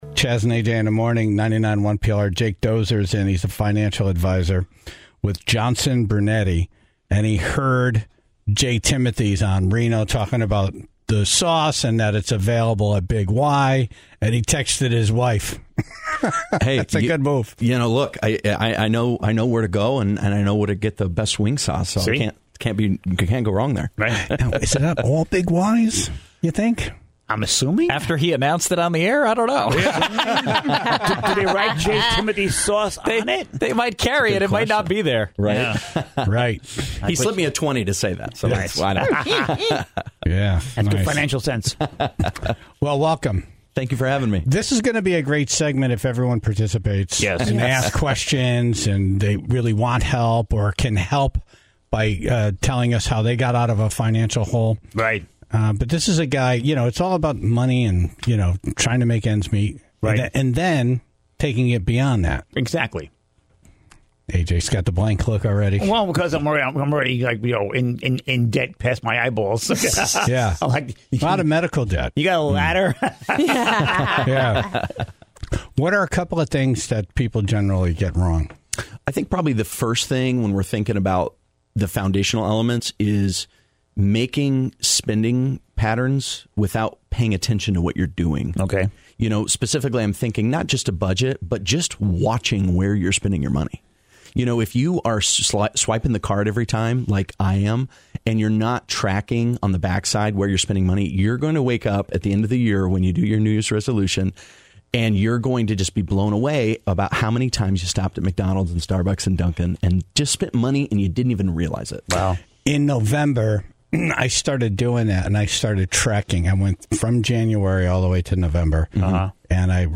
Financial Advisor
in studio this morning, to help the Tribe work on their financial health.